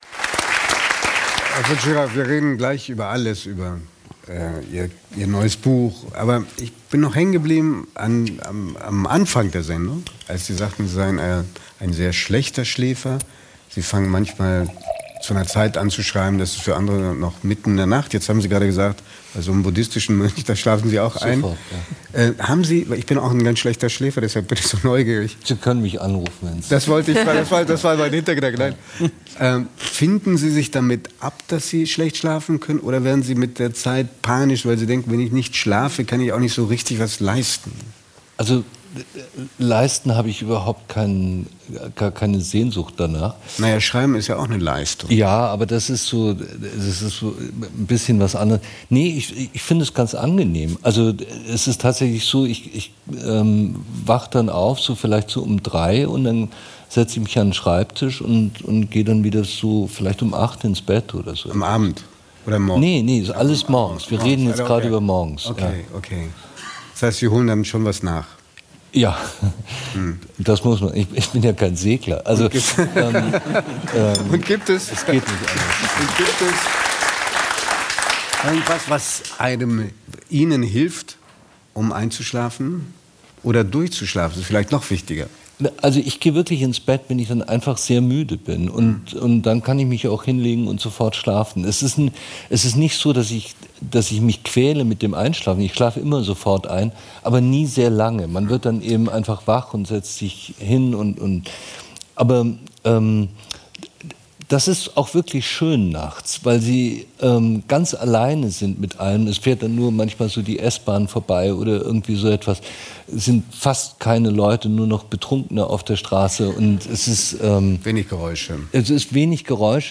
Er ist ein präziser Beobachter der menschlichen Abgründe mit einer leisen, aber eindringlichen Stimme: Ferdinand von Schirach.